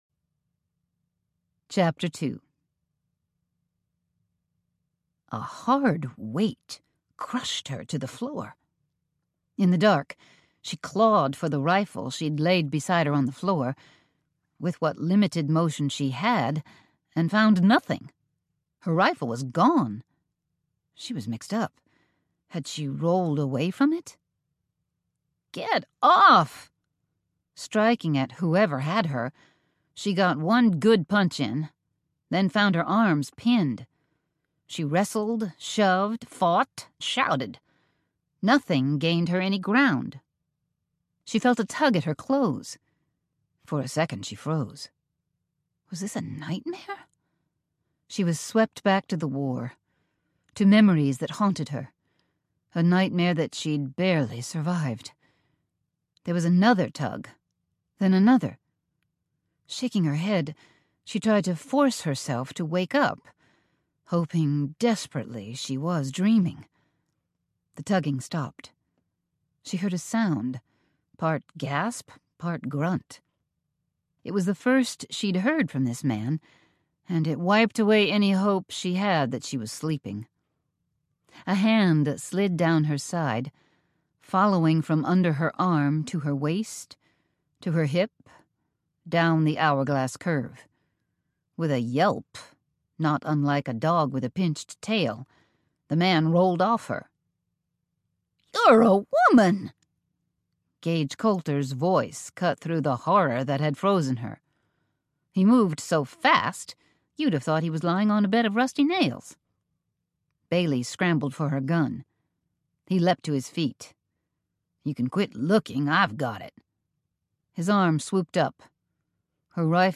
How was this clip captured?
9.5 Hrs. – Unabridged